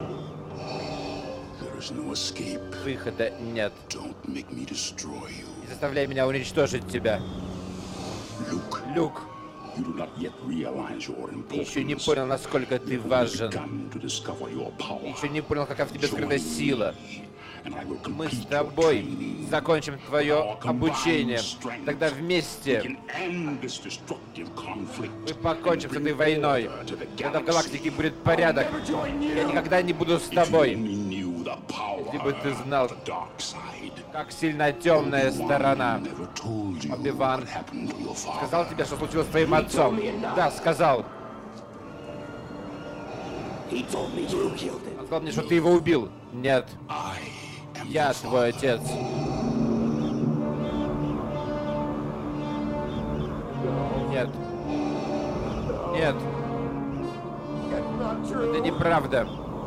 Речь Дарта Вейдера (Гнусавая озвучка)